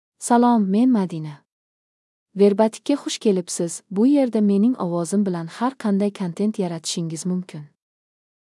Madina — Female Uzbek (Latin, Uzbekistan) AI Voice | TTS, Voice Cloning & Video | Verbatik AI
Madina is a female AI voice for Uzbek (Latin, Uzbekistan).
Voice sample
Listen to Madina's female Uzbek voice.
Madina delivers clear pronunciation with authentic Latin, Uzbekistan Uzbek intonation, making your content sound professionally produced.